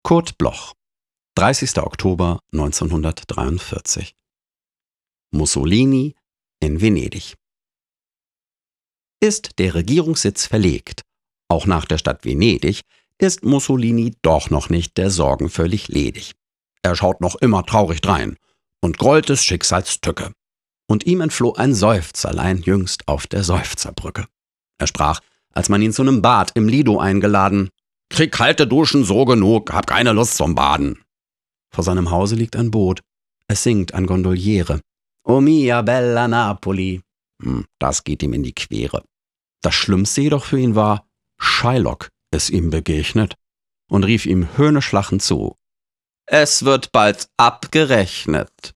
vorgetragen von Bodo Wartke